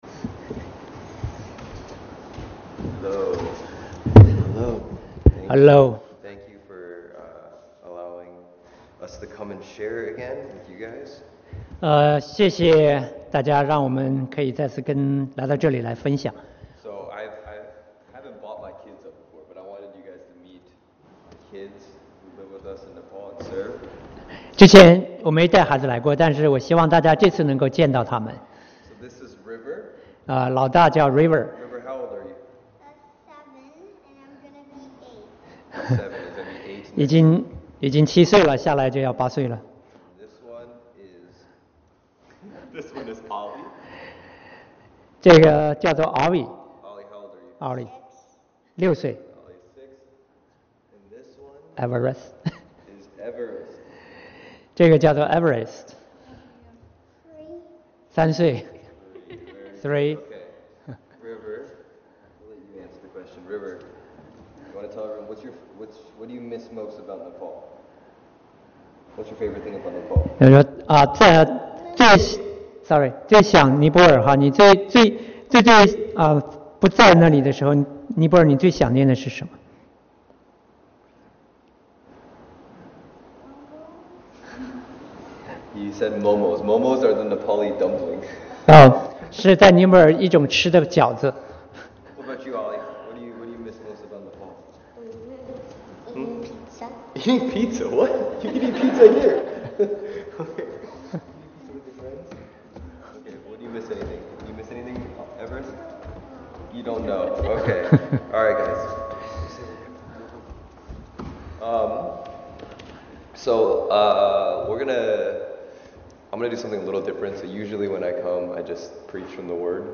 They answered questions about mental health, casting out demons, and interacting with other religions.